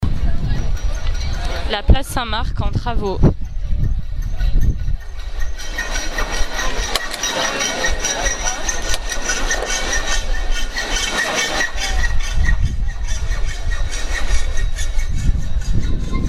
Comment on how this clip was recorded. Place Saint Marc en travaux, juin 2014.